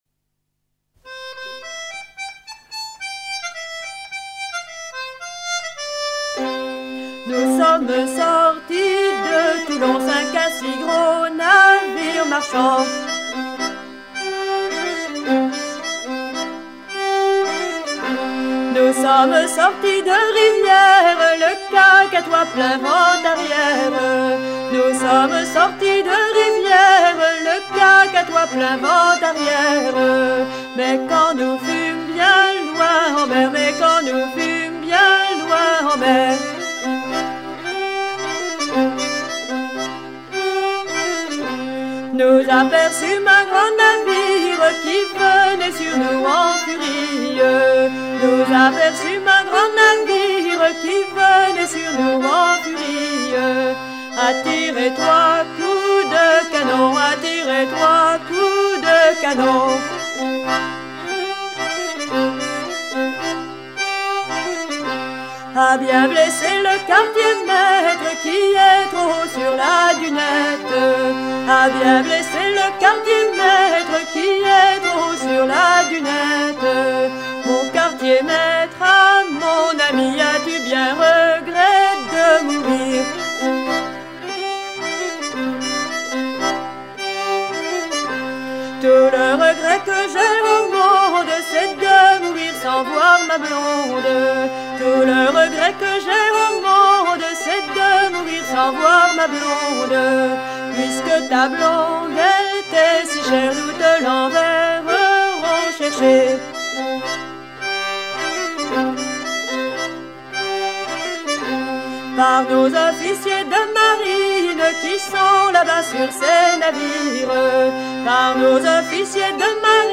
version chantée
Localisation Île-d'Yeu (L') (Plus d'informations sur Wikipedia)
Genre strophique
Edition discographique Chants de marins traditionnels, vol. I à V